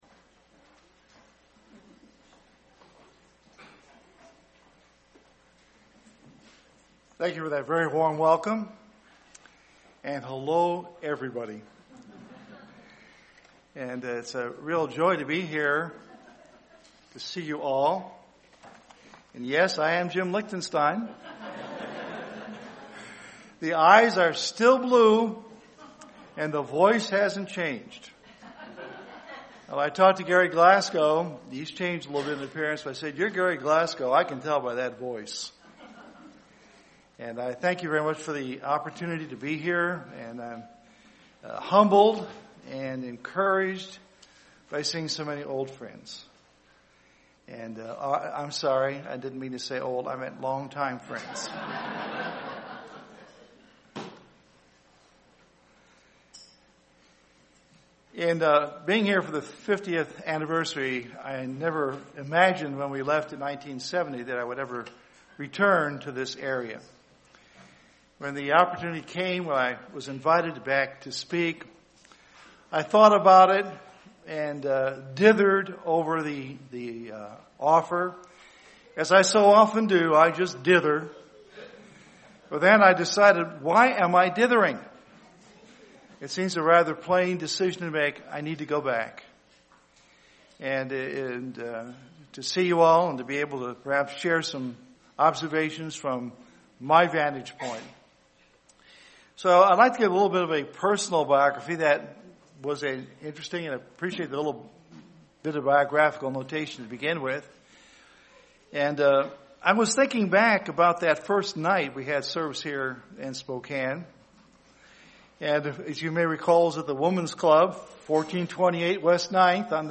First split sermon on Saturday, November 1, 2014 in Spokane, Washington, on the 50th anniversary of the Spokane congregation After 50 years, many things have changed but one thing has not - God's instruction to His people to do the work.